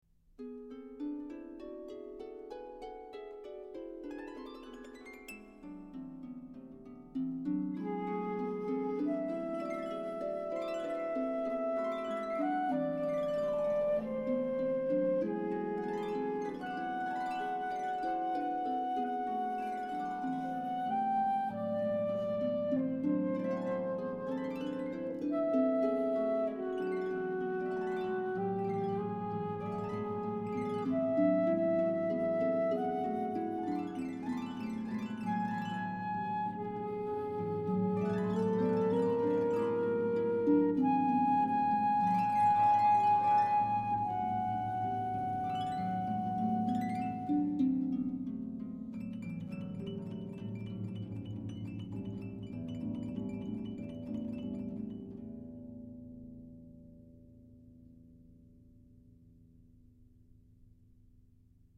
studio recording